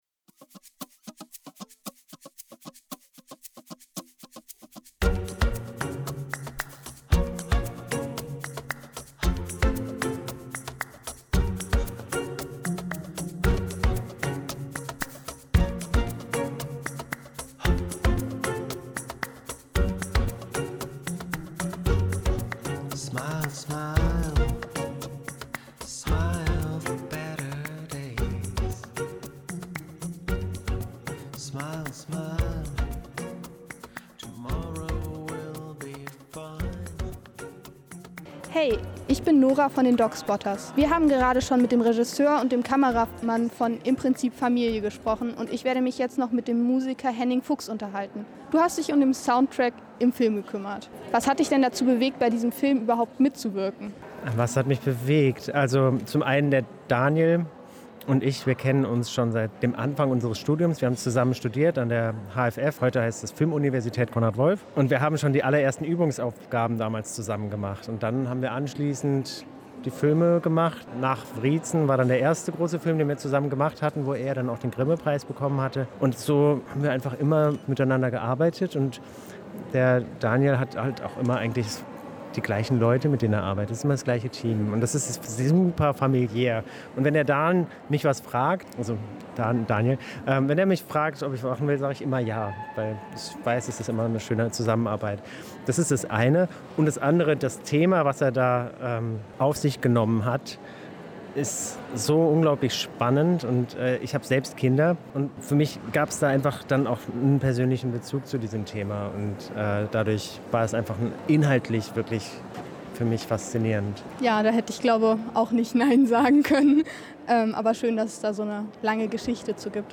Von DOK Spotters 2024Audio, Interview, Video